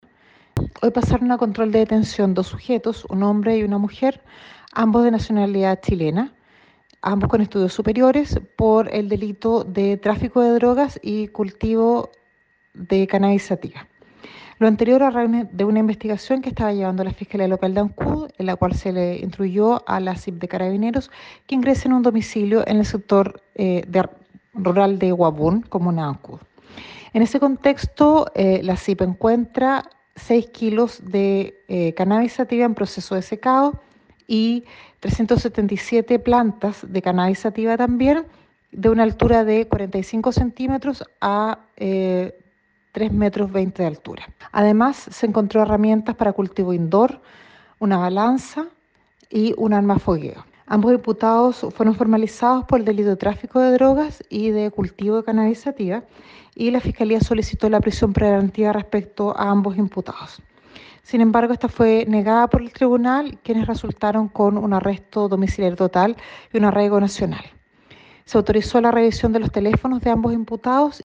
La fiscal (S), Pilar Werner, de la fiscalía local de Ancud, informó que dados los antecedentes expuestos en la audiencia ante el Juzgado de Garantía, se había solicitado al tribunal la prisión preventiva de este hombre y la mujer, sin embargo no se accedió a ello y en cambio, se impuso la cautelar de arresto domiciliario total y arraigo nacional.
04-FISCALIA-DE-ANCUD-PILAR-WERNER.mp3